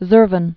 (zûrvən, zər-vän)